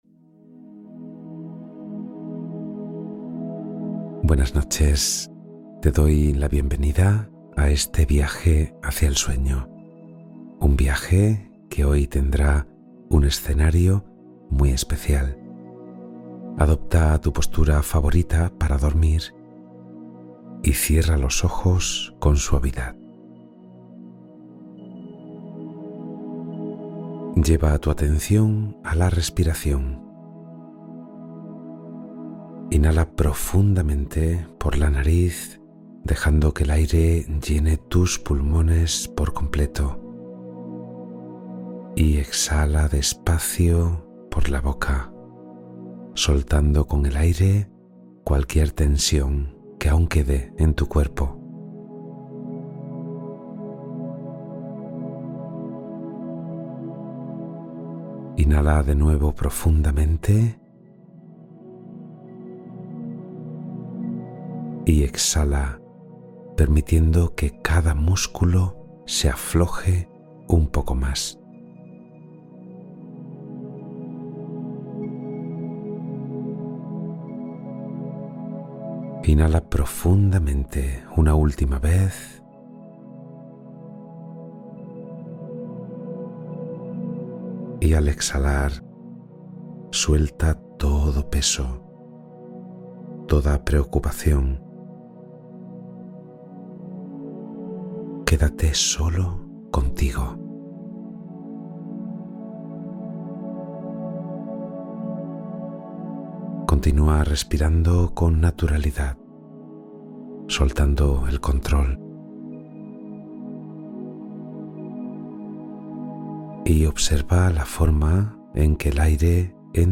Relato Interior y Meditación para un Descanso Estable